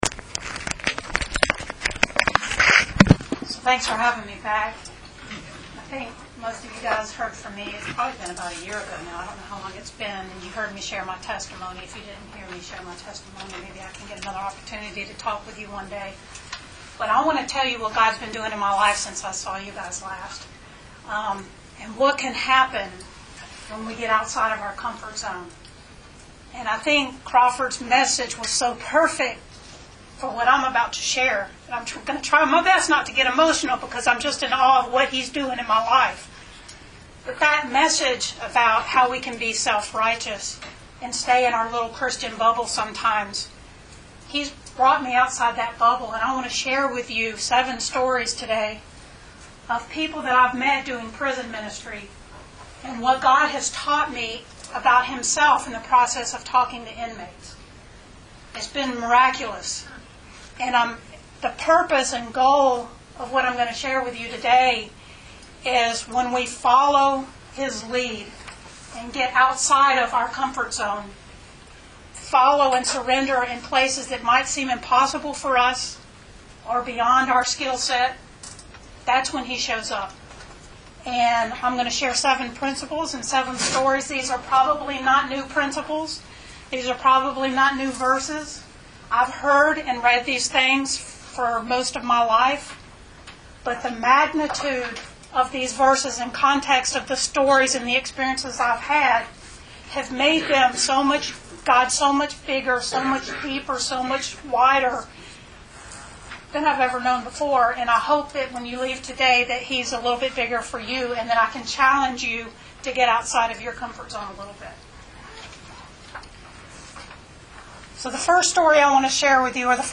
Recorded at Fellowship Bible Church in Roswell, GA – 11/1/15